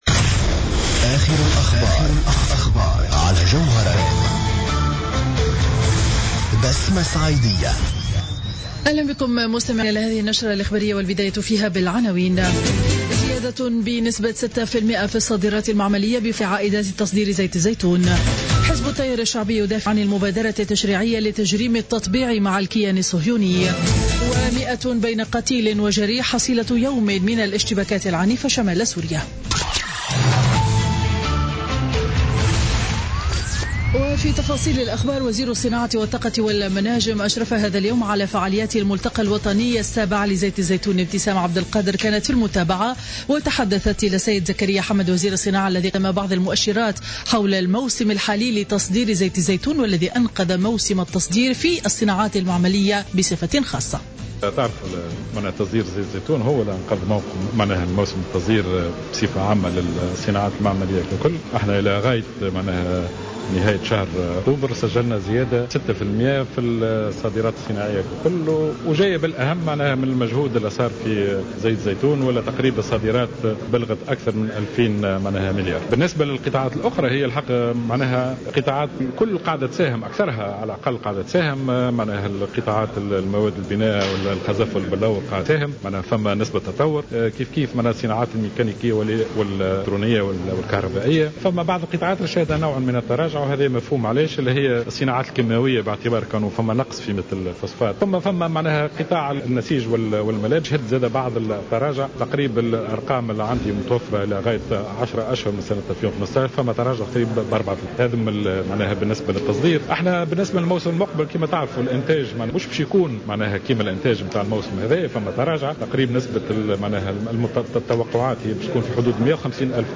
نشرة أخبار منتصف النهار ليوم الخميس 12 نوفمبر 2015